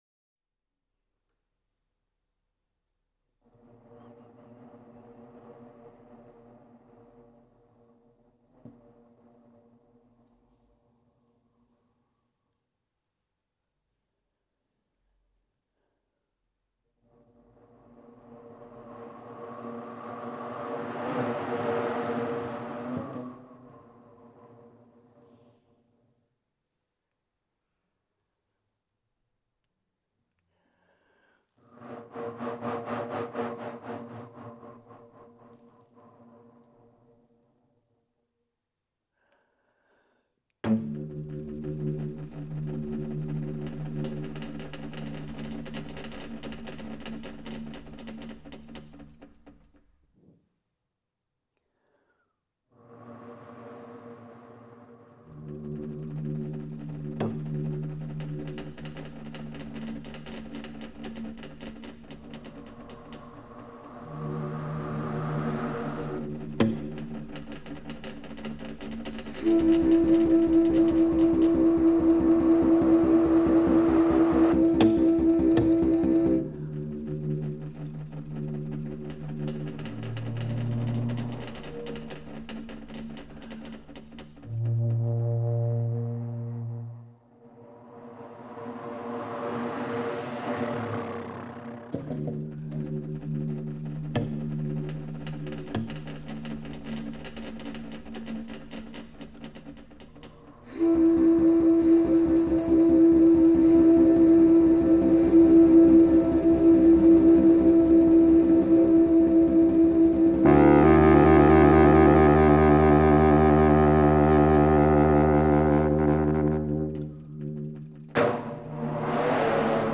8 Loudspeaker in a circle around the audience.
tuba